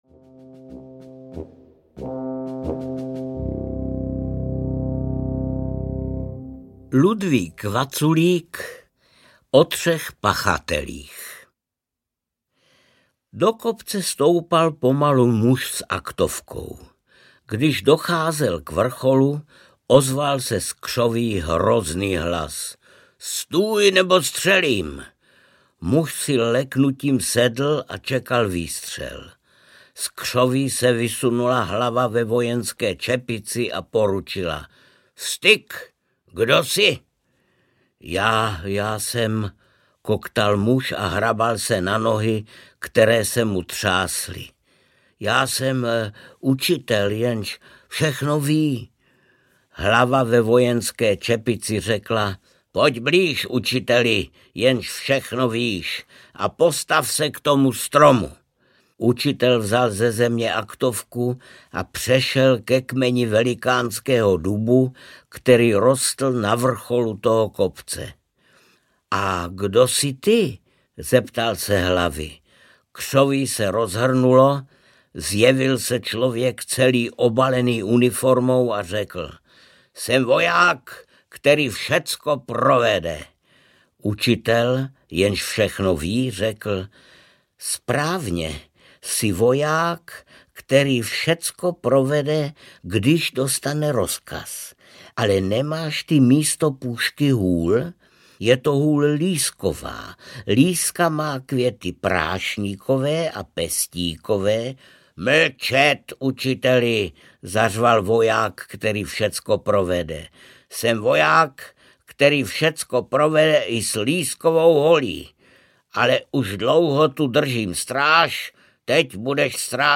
O třech pachatelích audiokniha
Ukázka z knihy
• InterpretArnošt Goldflam